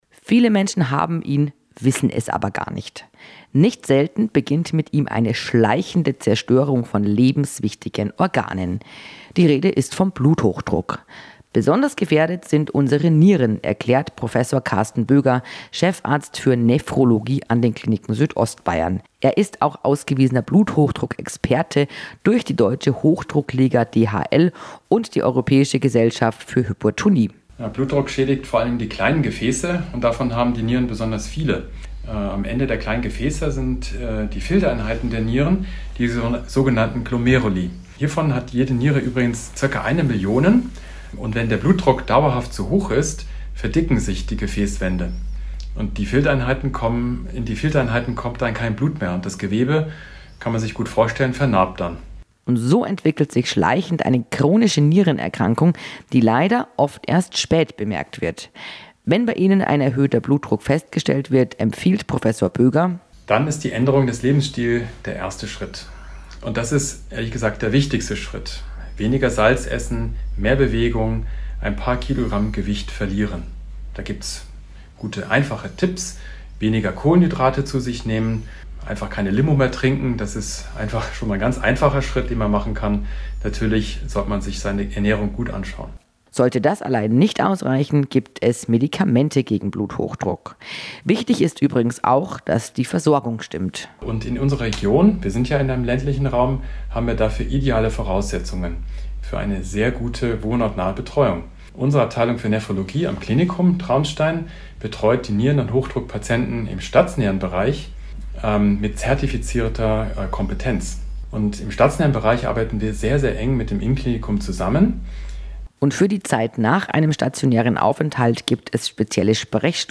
Im Format „Gesundheit KOMPAKT“ mit der Bayernwelle Südost sprechen Mediziner, Therapeuten und Pflegekräfte über medizinische Themen oder Aktuelles aus den Kliniken Südostbayern AG.